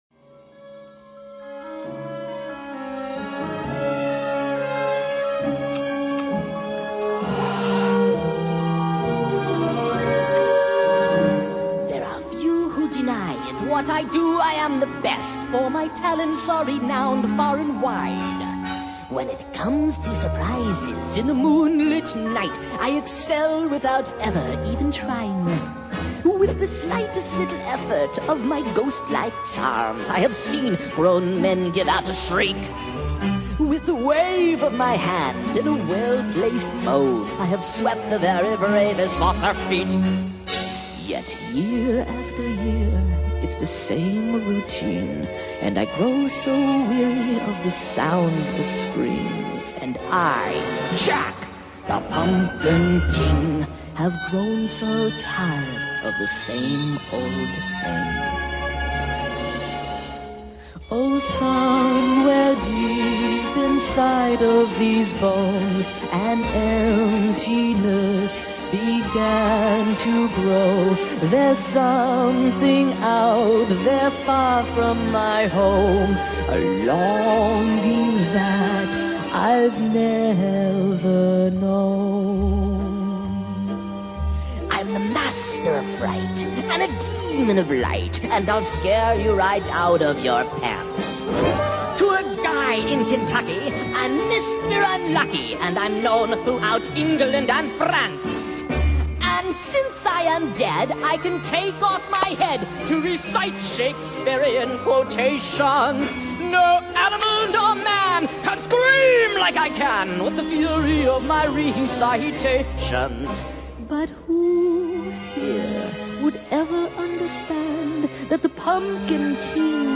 Full Song: Sped Up